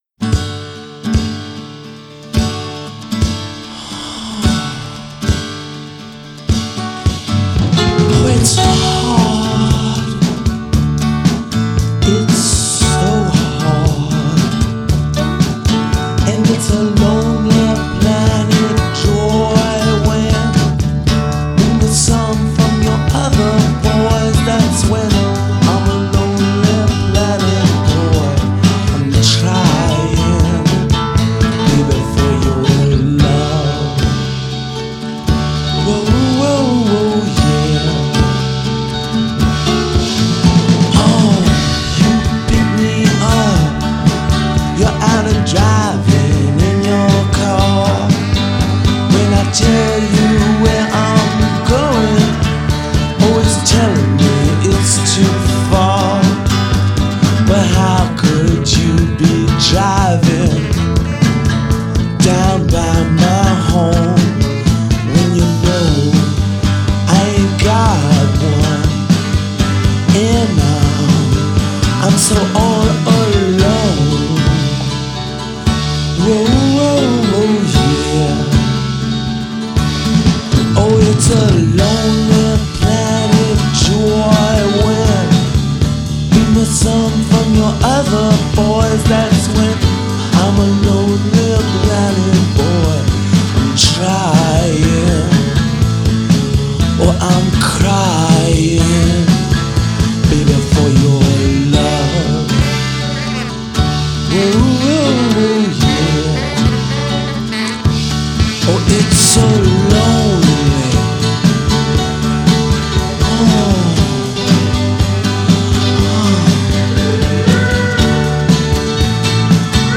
at acoustic balladry